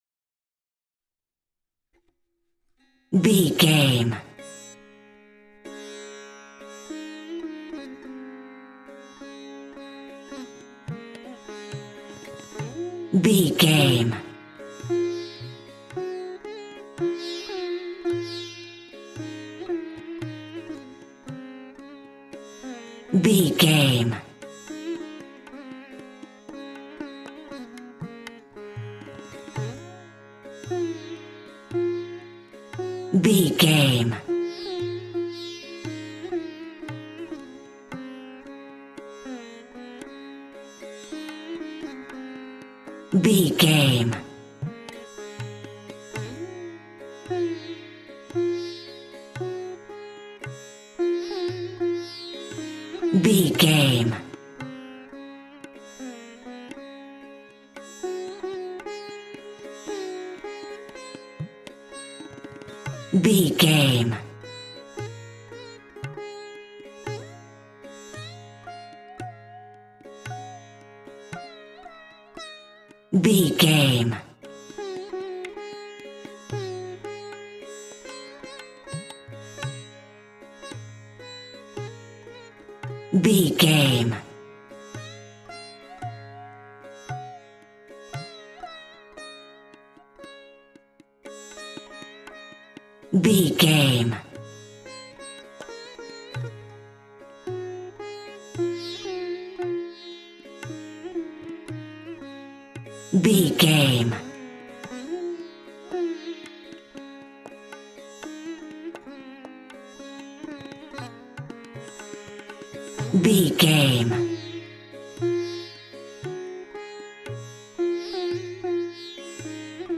Mixolydian
D♭
Slow
World Music
percussion